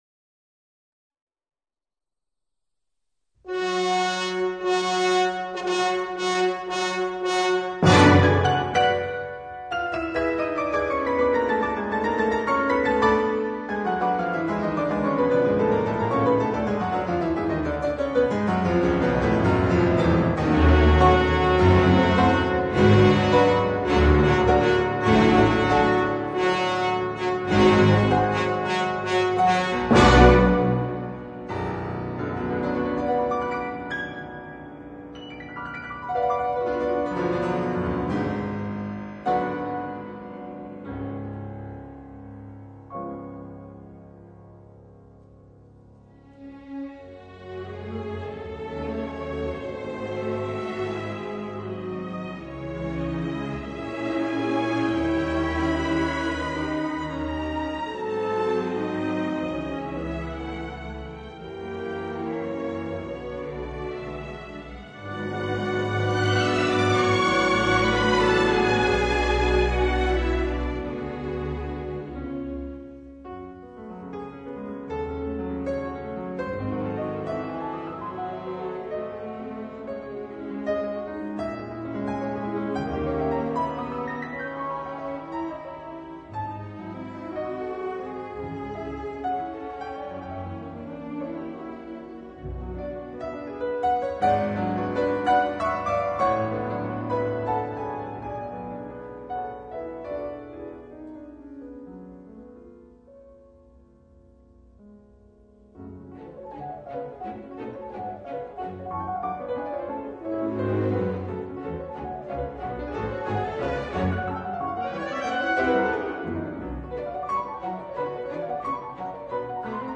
音樂類型：古典音樂
深沈浪漫的俄羅斯旋律多年來一直深受樂迷所熱愛。